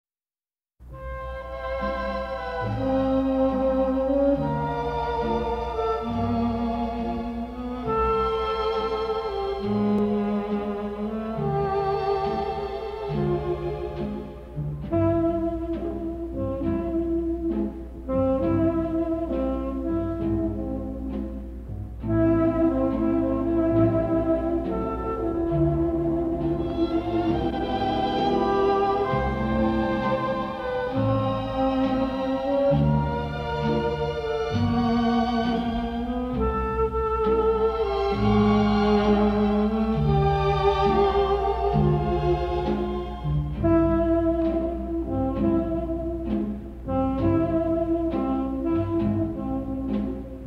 Las turbadoras aflicciones de tres mujeres (el todopoderoso productor Darryl F. Zanuck decidió finalmente suprimir una) tras recibir la carta de una amiga común que afirma haberse fugado con uno de sus maridos, sirvieron para ofrecer un incisivo retrato del entorno cotidiano y social en una pequeña ciudad de provincias, desarrollado a través de una prodigiosa serie de flashbacks paralelos (sincronizados con la atrayente voz en off de Celeste Holm) que aludían a las aprensivas relaciones conyugales de sus heterogéneos personajes.